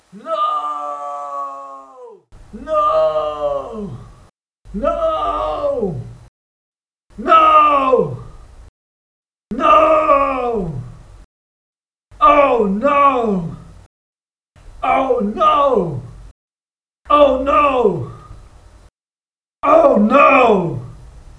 oh no.m4a
Category 🗣 Voices
feminine no oh-no speech talk talking voice word sound effect free sound royalty free Voices